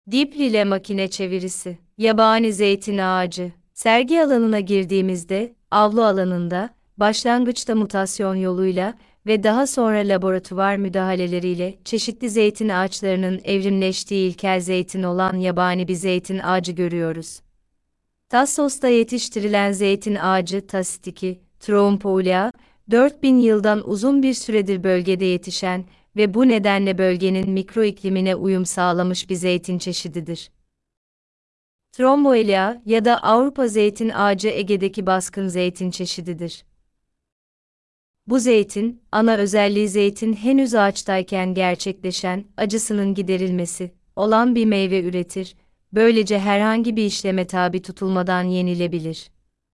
Sesli rehberli tur